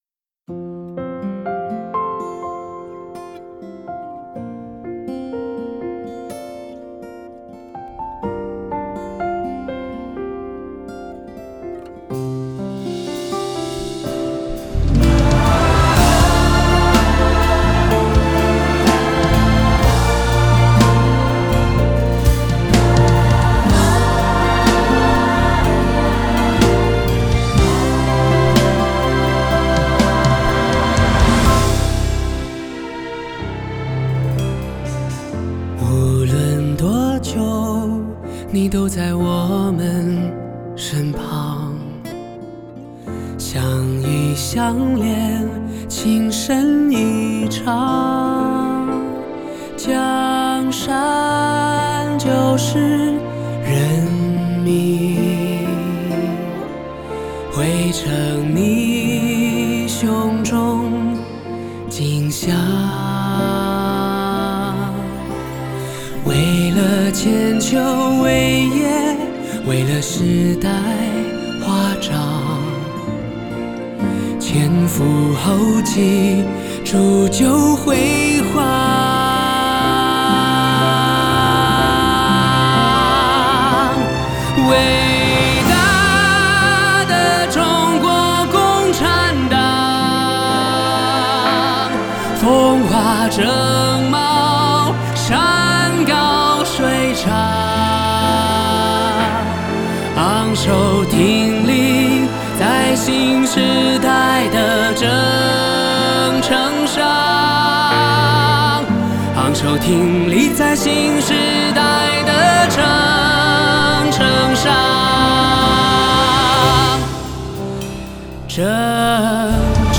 弦乐
铜管
木管
合唱